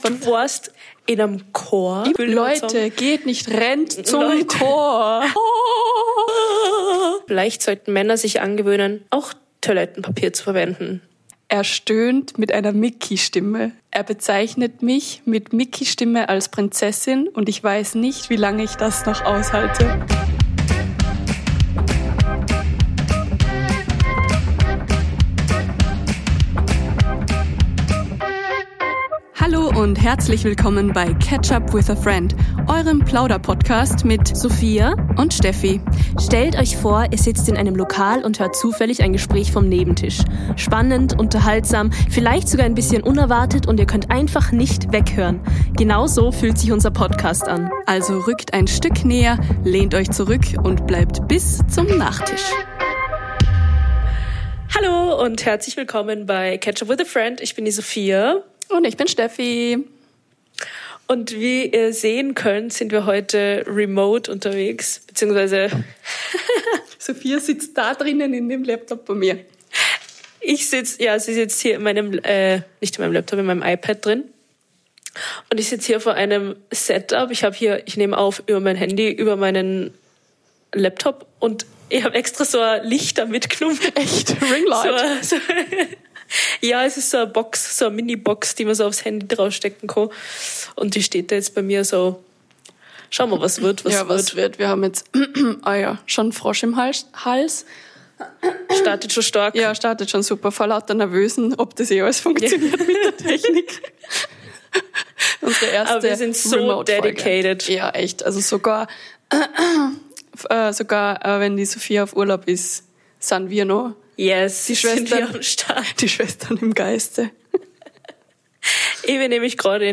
Wir lesen Reddit-Storys, kommentieren sie (mal mit Verständnis, mal mit Schockzustand) und erzählen, wo uns das selbst schon mal passiert ist - oder fast.
Eine Folge zwischen Cringe, Comedy und kollektivem Kopfschütteln.